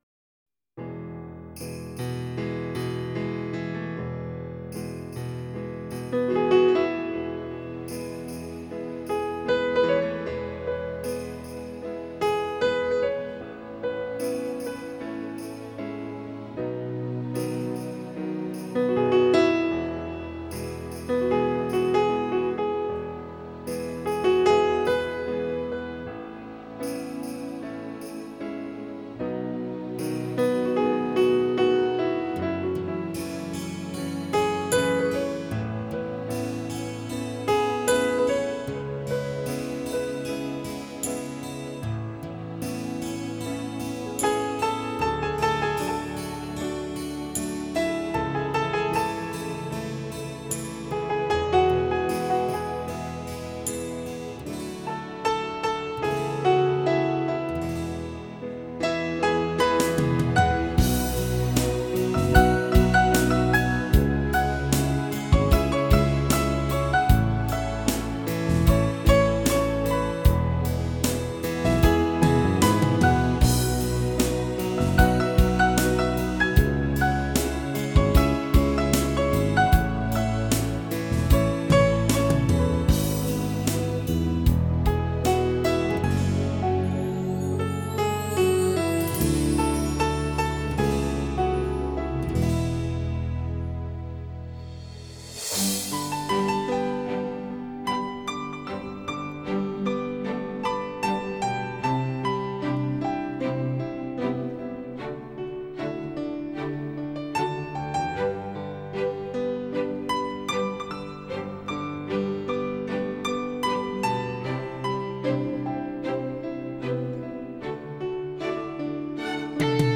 version piano